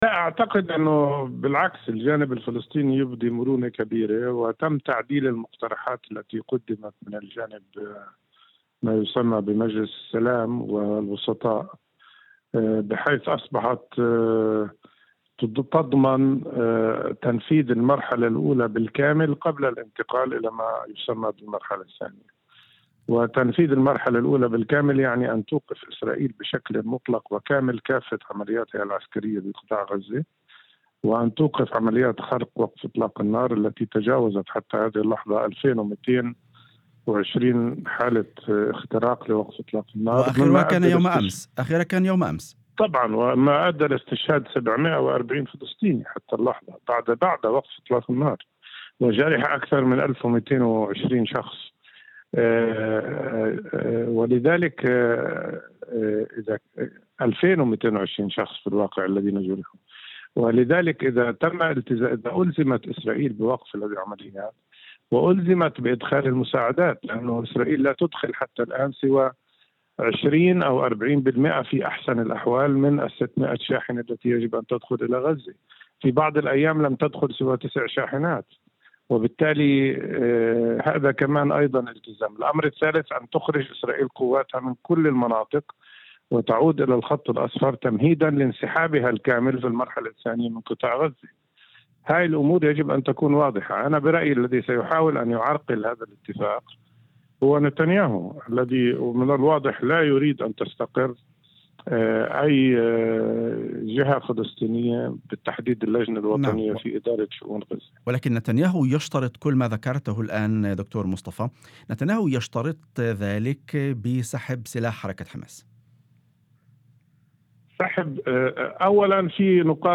وأضاف في مداخلة هاتفية ضمن برنامج "يوم جديد"، على إذاعة الشمس، أن من بين الشروط أيضًا إدخال المساعدات بشكل كافٍ، مشيرًا إلى أن "إسرائيل لا تسمح إلا بدخول 20% إلى 40% من الشاحنات المطلوبة، وفي بعض الأيام لم تدخل سوى 9 شاحنات"، إلى جانب ضرورة انسحاب القوات الإسرائيلية من المناطق التي دخلتها.